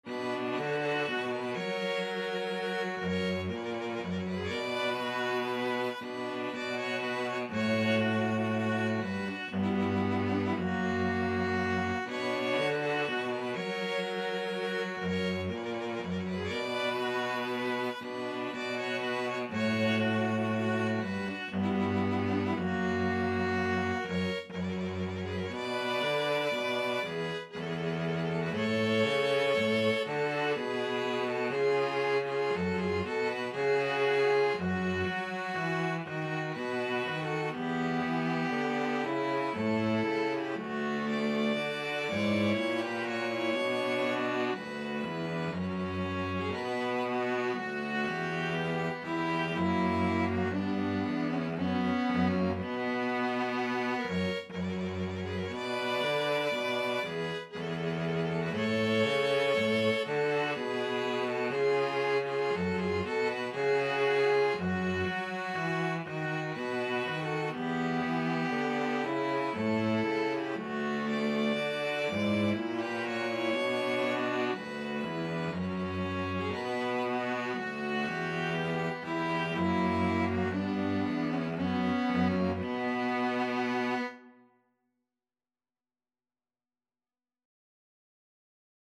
ViolinViolaCello
3/4 (View more 3/4 Music)
Vivace (View more music marked Vivace)
String trio  (View more Intermediate String trio Music)
Classical (View more Classical String trio Music)